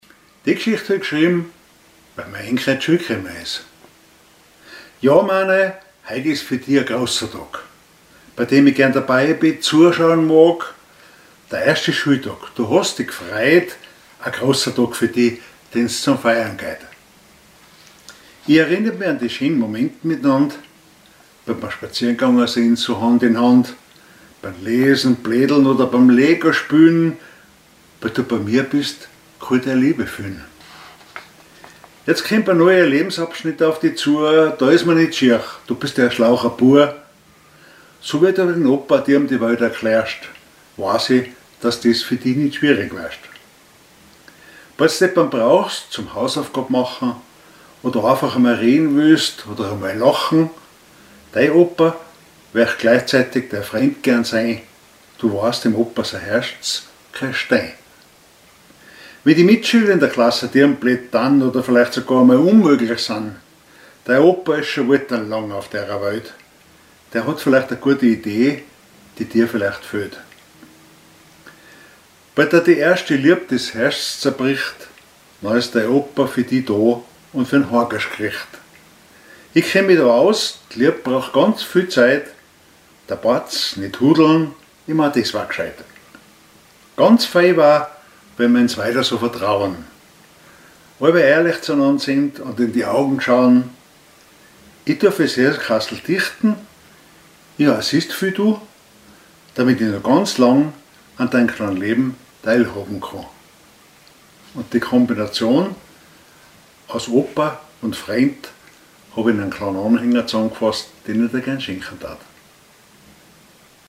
Type: Liebesgedicht